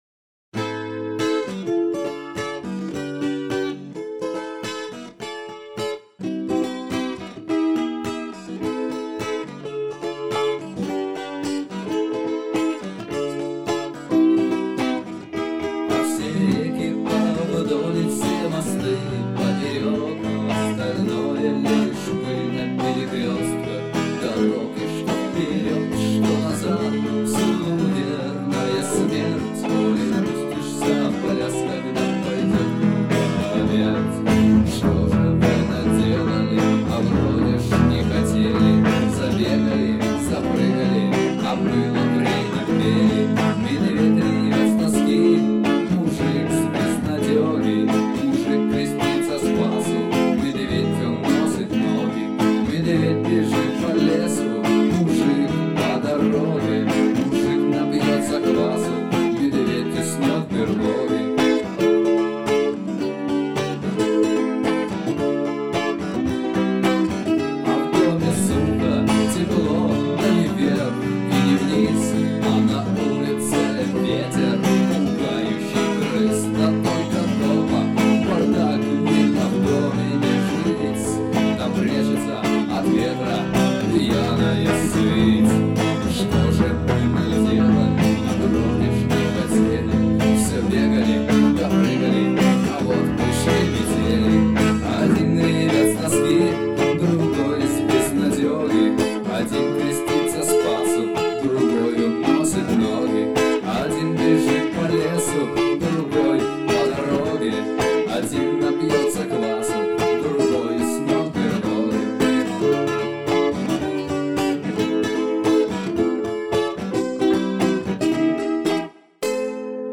Семипалатинск, реставрированные записи - mp3.